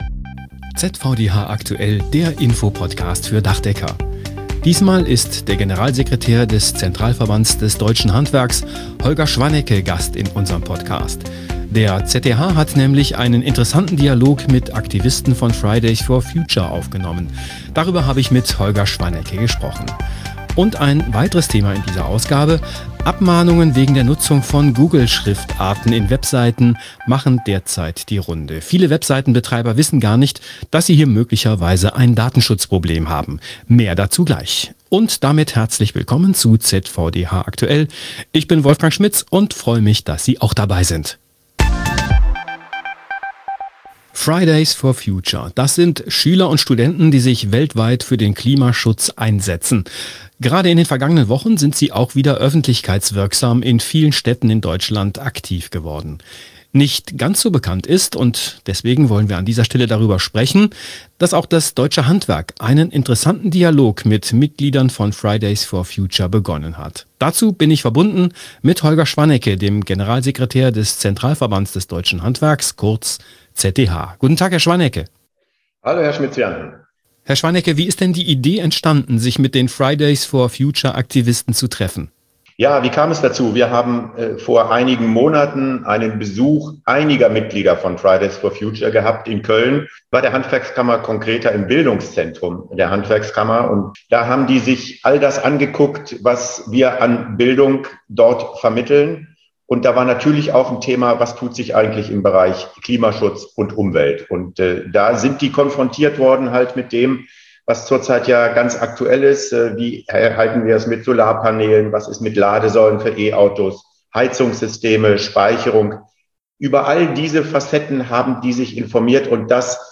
im Gespräch | Abmahnrisiko durch Google-Fonts: der ZVDH hilft